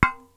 rebound.wav